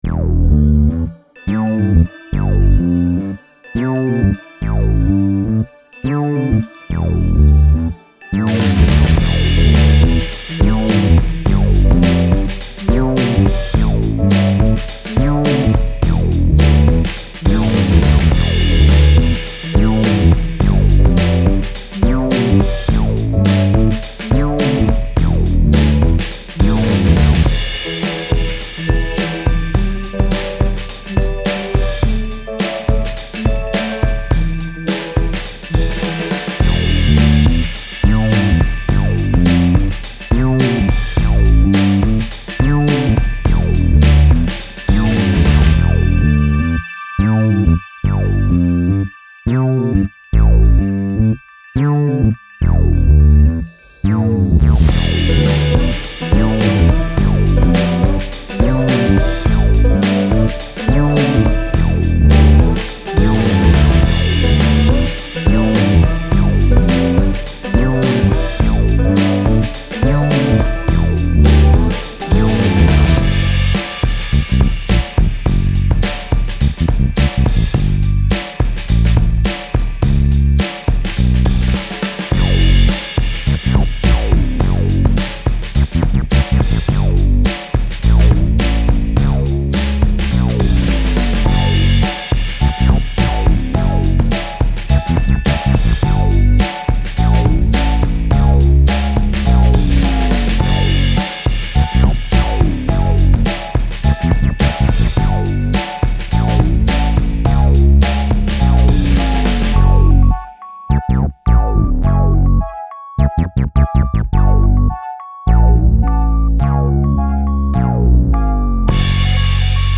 Style Style EDM/Electronic
Mood Mood Bright, Cool, Intense +1 more
Featured Featured Bass, Drums, Piano +1 more
BPM BPM 105